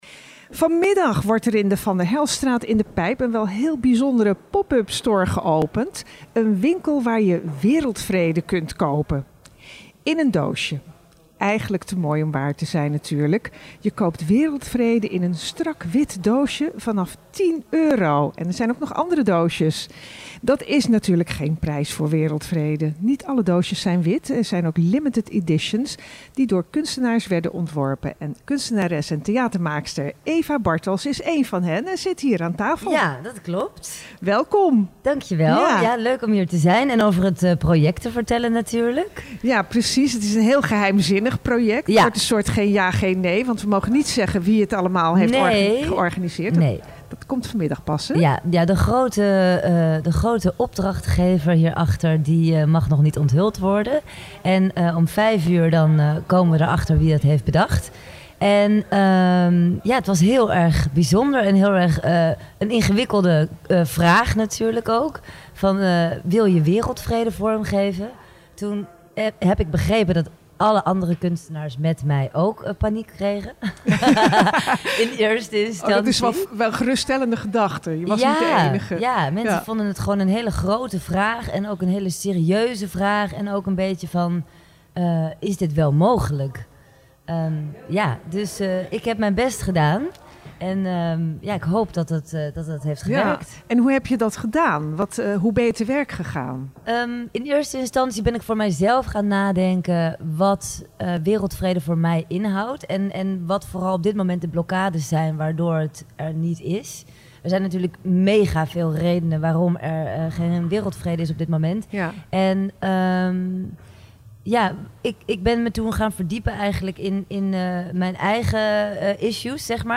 Ten tijde van het gesprek was nog niet bekend wie er achter dit geheimzinnige project zat, maar inmiddels weten we dat dit Amnesty International is!